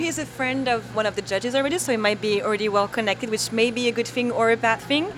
HARVARD PROF IN COFFEE SHOP IN PROVINCETOWN SPEAKS ABOUT UNITED STATES SUPREME COURT NOMINEE MERRICK GARLAND KNOWING OTHER JUDGES ON THE COURT